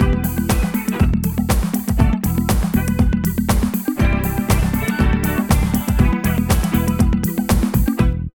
78 LOOP   -R.wav